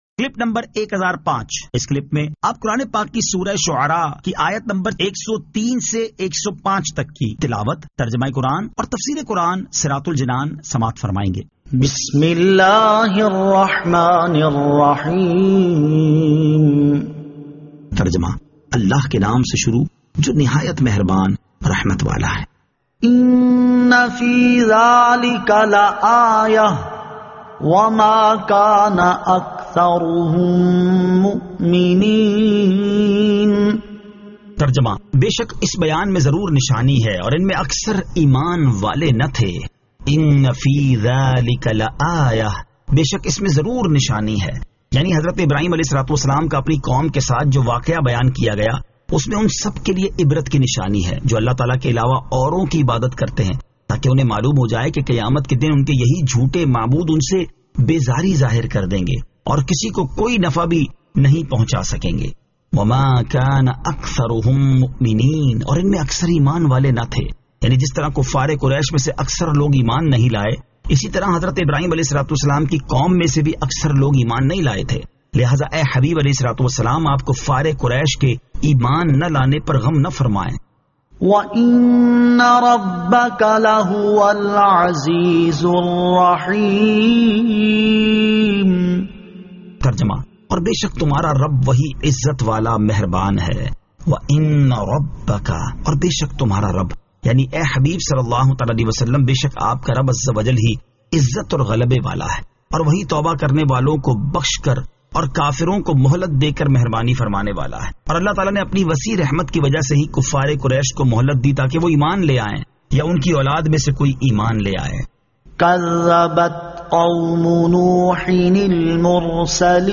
Surah Ash-Shu'ara 103 To 105 Tilawat , Tarjama , Tafseer